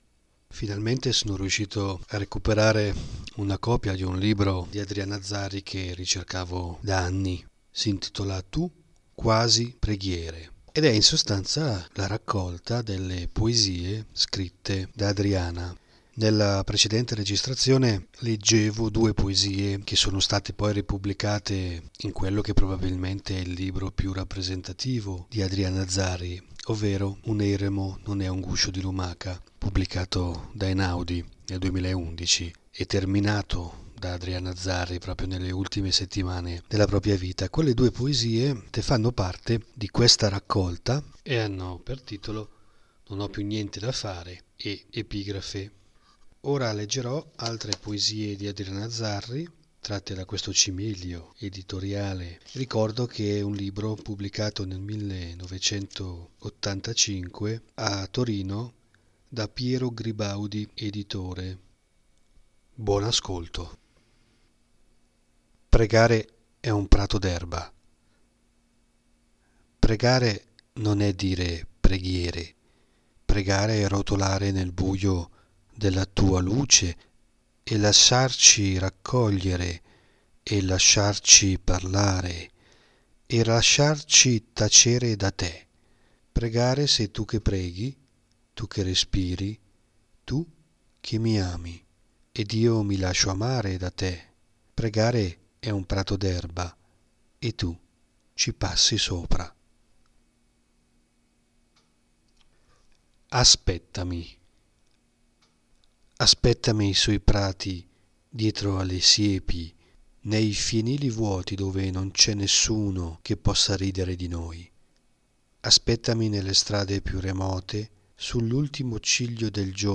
A seguire una selezione di brani e passi letti per voi.
Dettaglio: letture di poesie dalla raccolta fuori catalogo Tu. Quasi preghiere (Piero Gribaudi editore, Torino, 1985). Le poesie, in ordine di lettura, sono: Pregare è un prato d’erba, Aspettami, Andavi al Pozzo, Piedi nuovi.